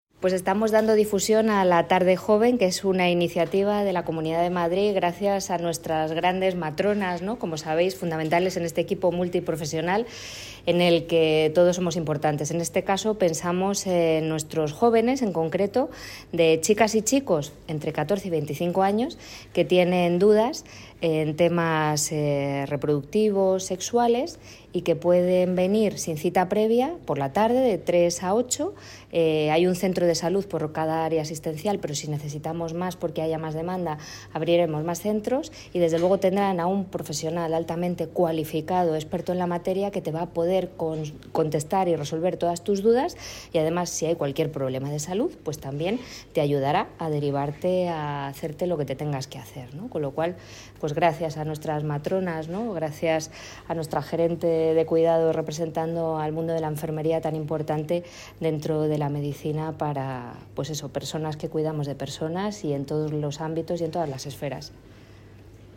251016_fatima_matute_-_consejera_sanidad_-_tarde_joven.mp3